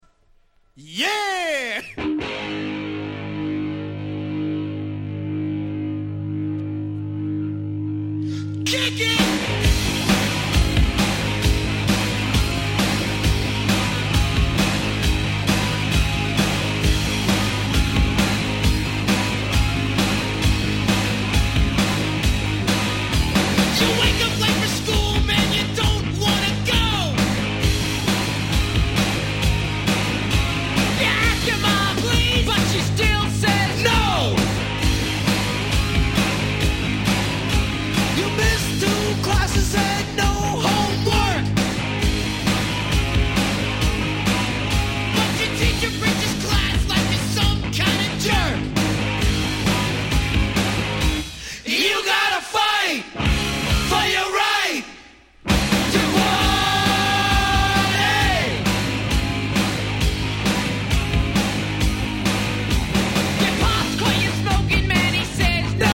Hip Hop史上に燦然と名を残す名盤中の名盤！！
Rock調の楽曲あり、ClassicなOld Schoolありの飽きの来ない最強の名盤！！